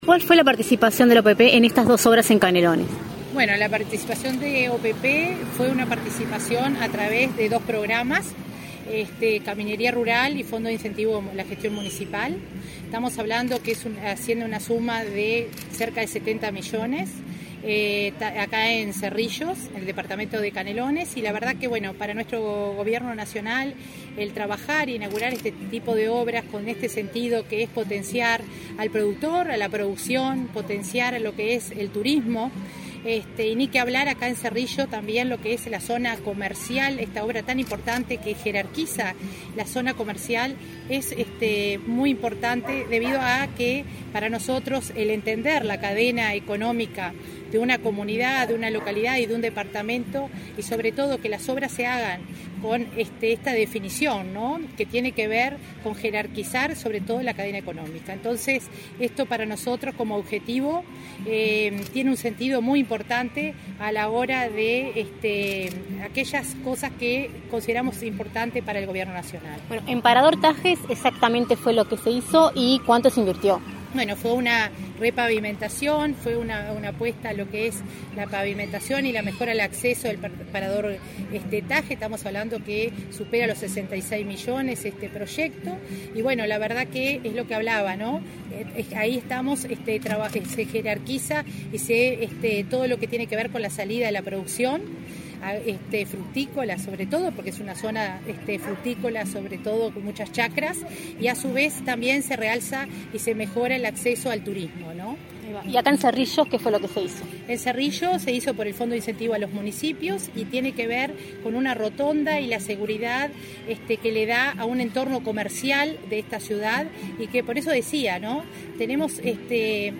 Declaraciones a la prensa de la coordinadora de Descentralización y Cohesión de OPP, María de Lima
Declaraciones a la prensa de la coordinadora de Descentralización y Cohesión de OPP, María de Lima 14/03/2022 Compartir Facebook X Copiar enlace WhatsApp LinkedIn Tras participar en la inauguración de obras de la Oficina de Planeamiento y Presupuesto (OPP), este 14 de marzo, en Canelones, la directora María de Lima efectuó declaraciones a la prensa.